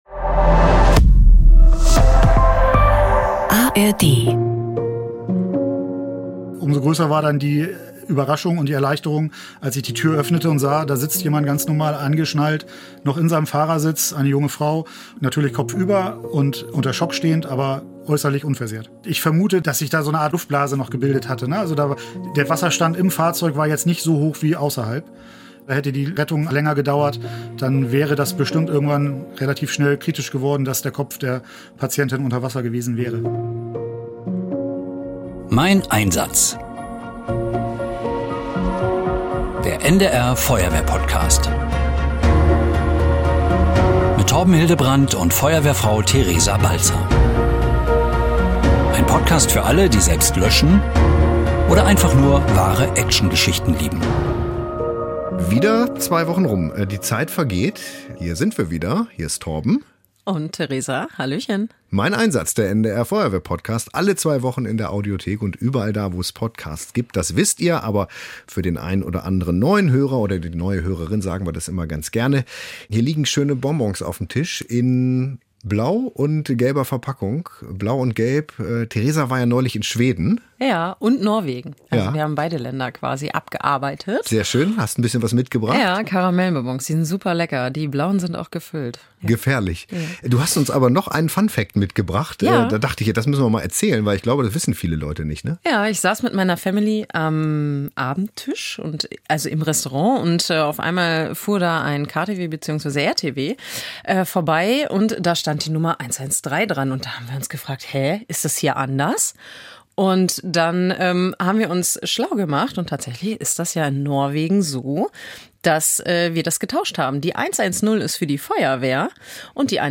Im Podcast "Mein Einsatz" sprechen Freiwillige Feuerwehrleute